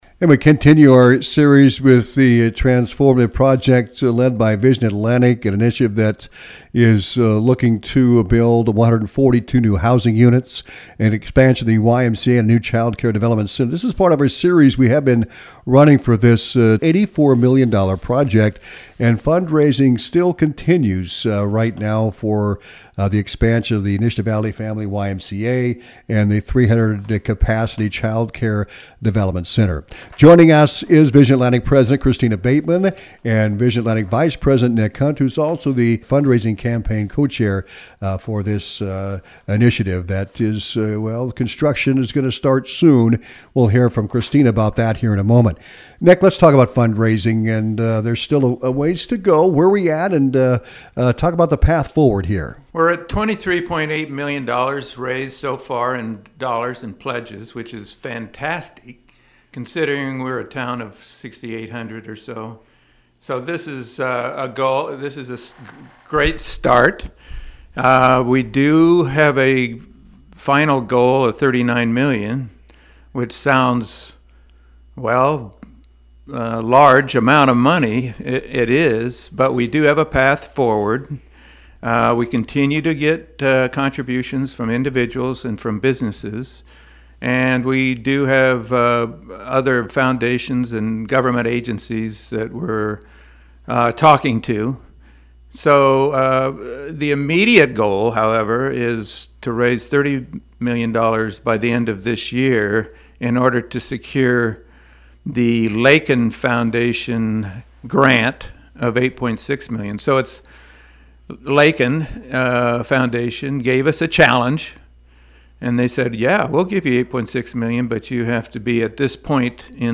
vision-atlantic-interview-august-27.mp3